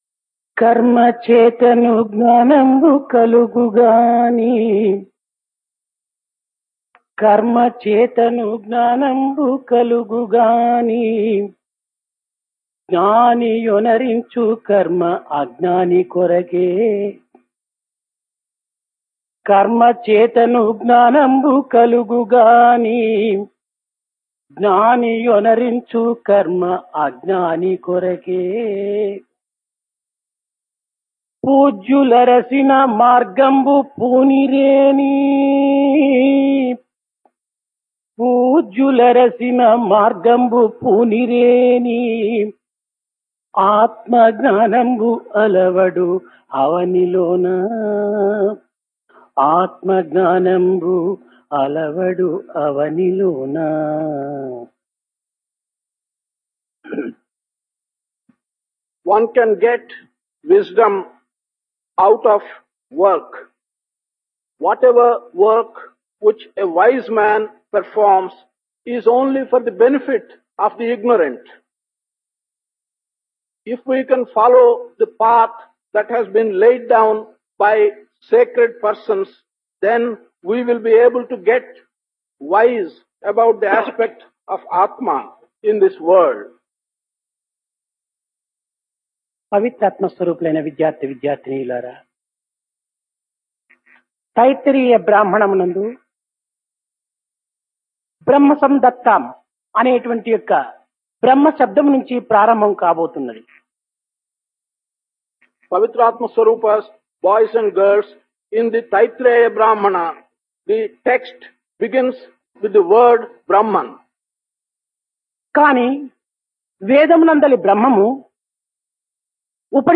Discourse
Place Brindavan Occasion Summer Course 1974 - Brahman